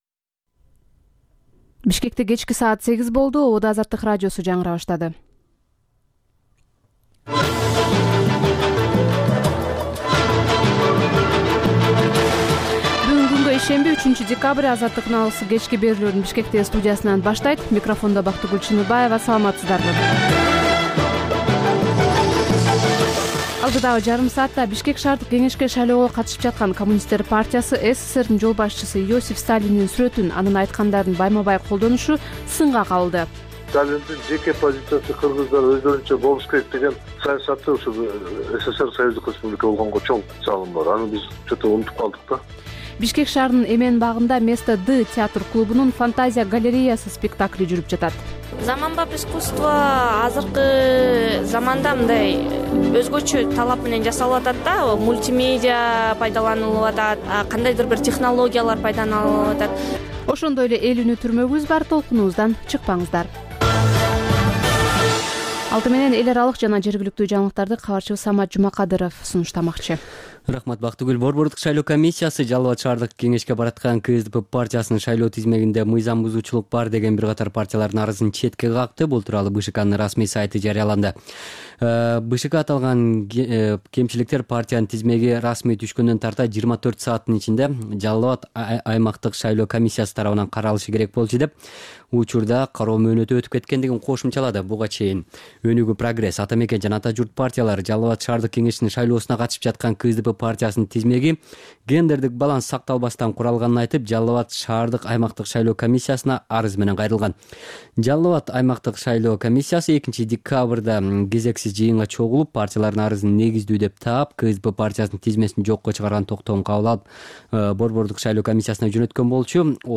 Бул үналгы берүү ар күнү Бишкек убакыты боюнча саат 20:00дан 21:00гө чейин обого түз чыгат.